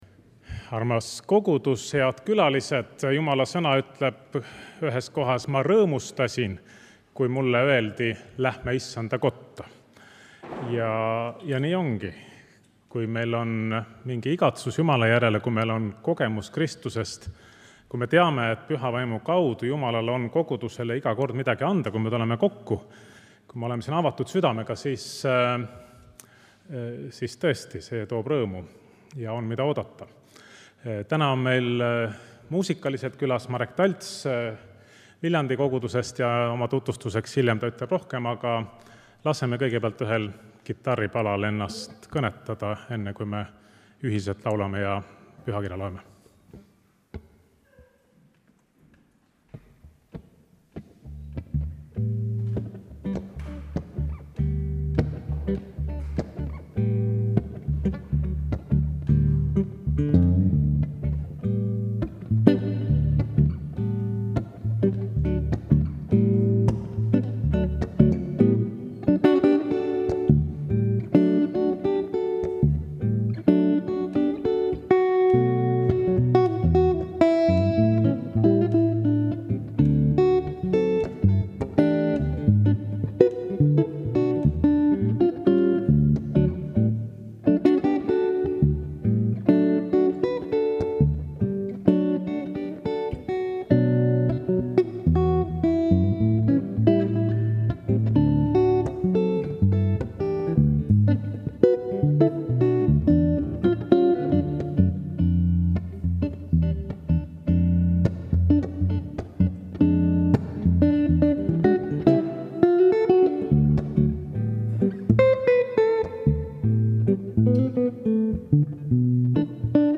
Pühakirja lugemine ja palve